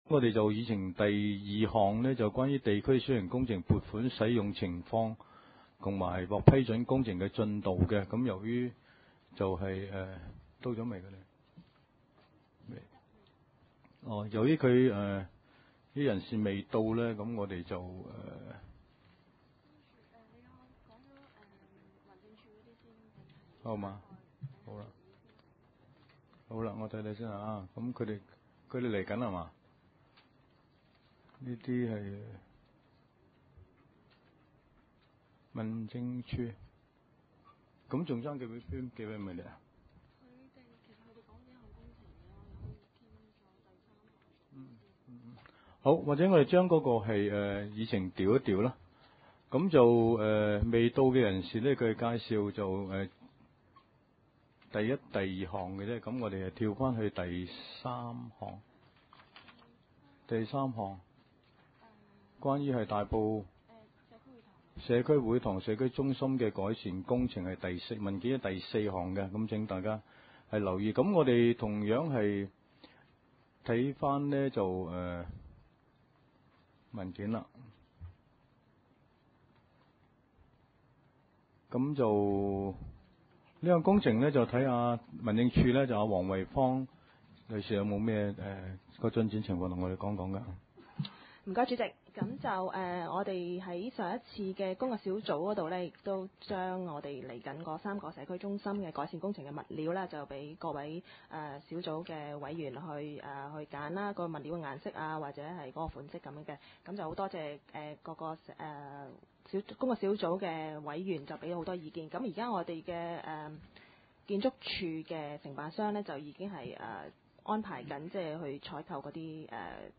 大埔區議會 地區設施管理委員會 2008 年第五次會議 日期：2008年9月16日 (星期二) 時間：上午9時30分 地點：大埔區議會秘書處會議室 議 程 討論時間 I. 通過地區設施管理委員會 2008 年第四次會議 (22.7.2008) 記錄 00:31 (大埔區議會文件DFM 33/2008號) II. 地區小型工程撥款的使用情況及獲批工程進度報告